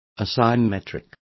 Also find out how asimetrica is pronounced correctly.